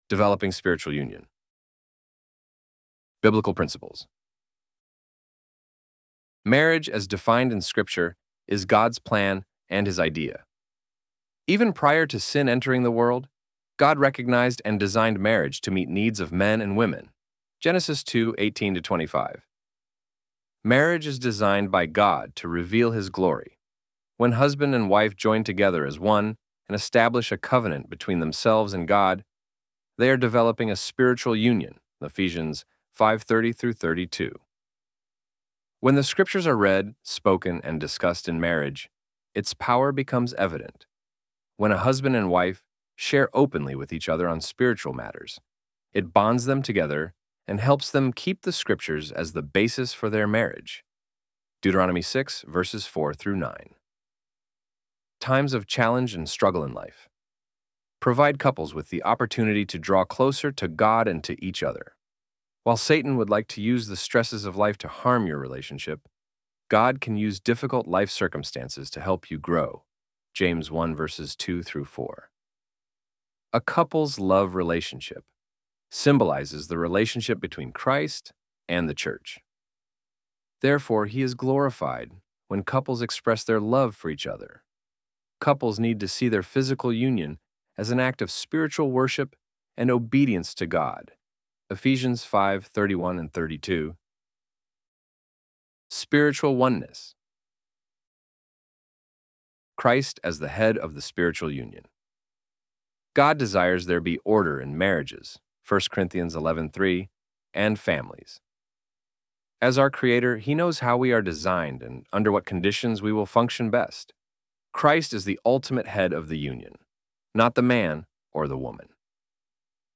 ElevenLabs_Developing_Spiritual_Union_-.mp3